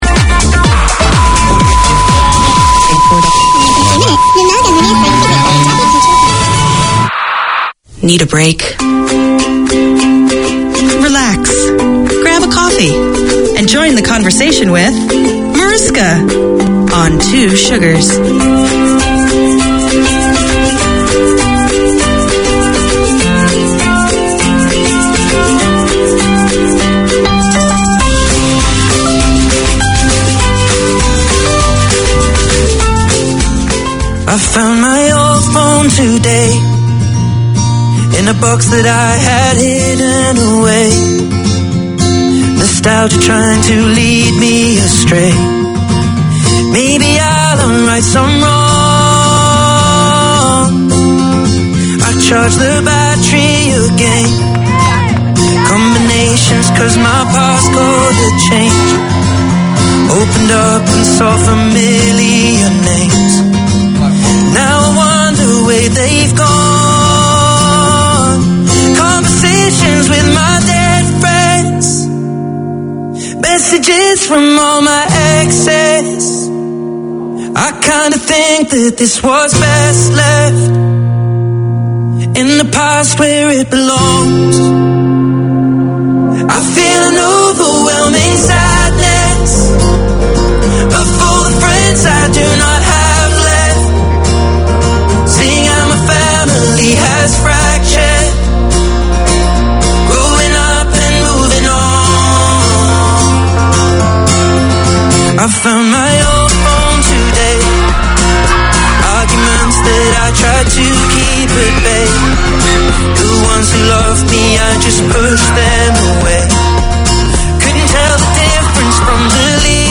Community Access Radio in your language - available for download five minutes after broadcast.
From local legends in grassroots to national level names, the Sports Weekender features interviews, updates and 'the week that was'.